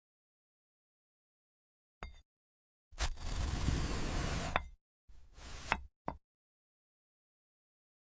Glass Apple ASMR – clean sound effects free download
clean Mp3 Sound Effect Glass Apple ASMR – clean cuts, calm sounds.